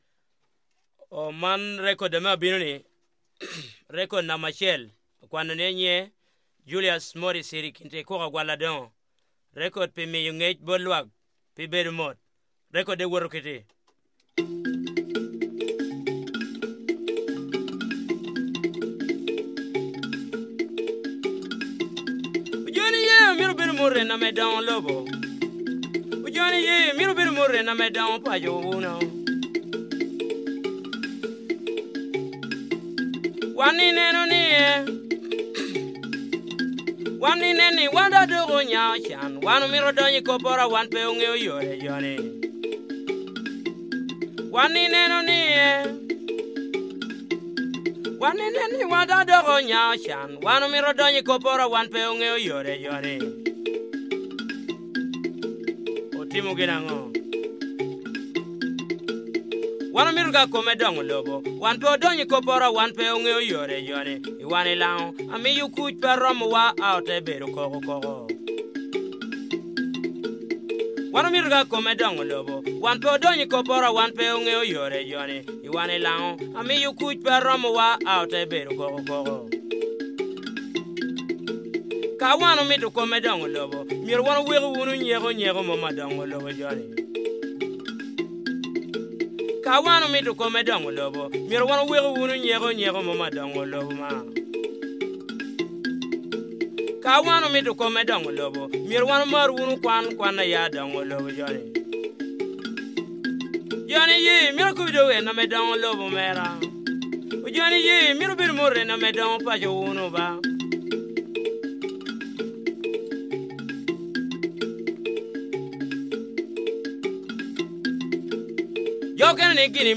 The okeme is also favoured by older singer-poets to accompany their often solo performances that comment on social conditions and behaviour, as well as give advice on such matters as health.